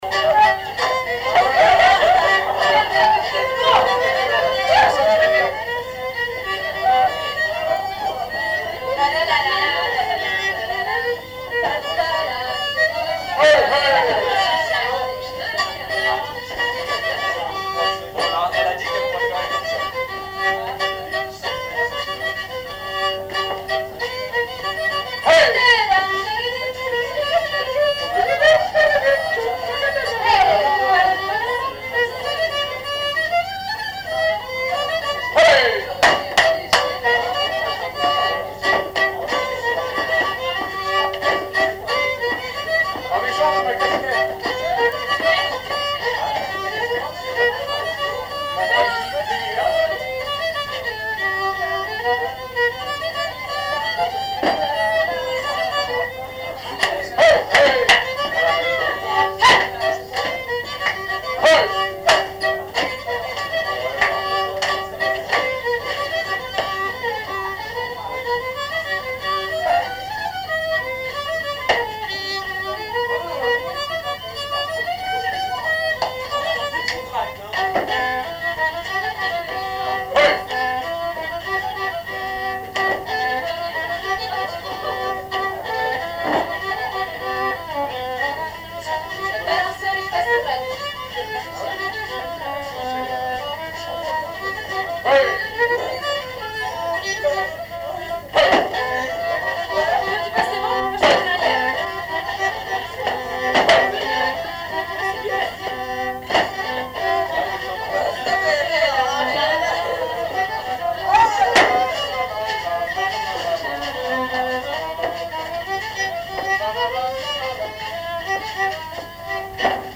danse : branle : avant-deux
Répertoire d'un bal folk par de jeunes musiciens locaux
Pièce musicale inédite